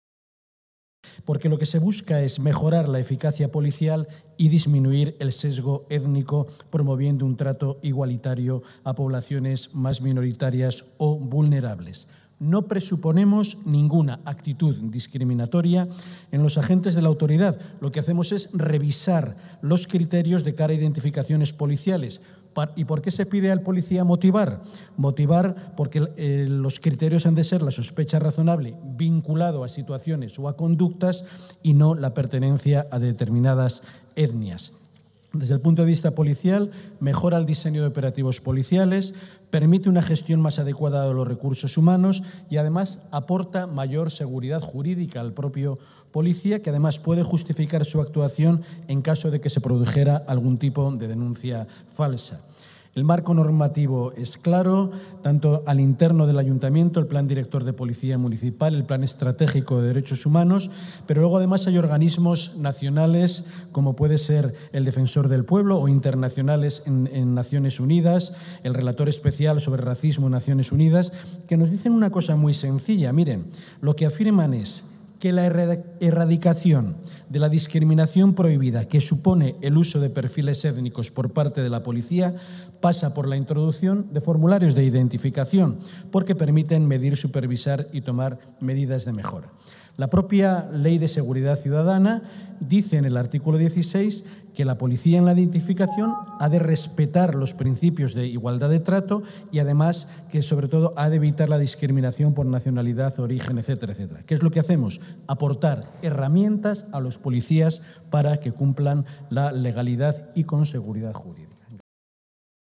Javier Barbero en su intervención esta mañana durante el Pleno El uso de estos documentos está respaldado y recomendado por organismos internacionales como Naciones Unidas o la Agencia Europea de Derechos Humanos, entre otras.
JBarberoPlenoIdentificacionesPoliciales-25-10.mp3